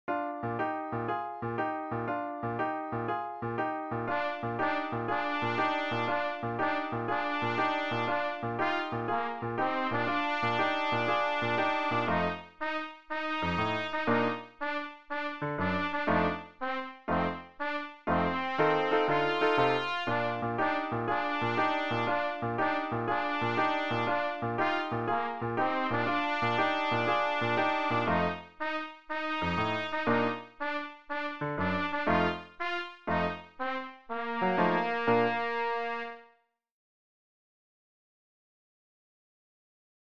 Beginner Trumpet Solo with Piano Accompaniment